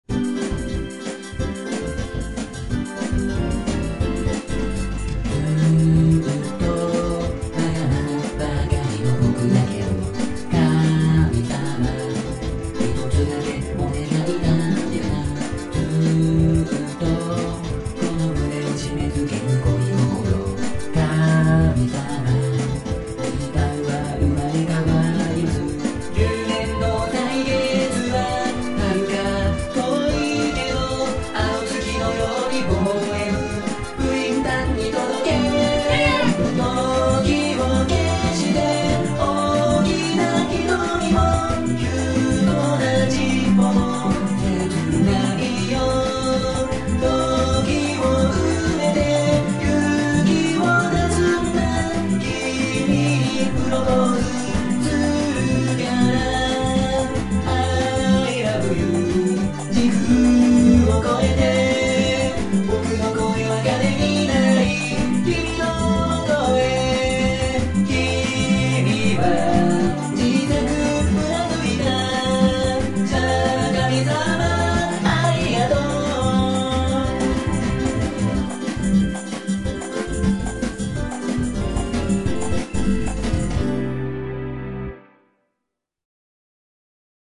【1.6倍速】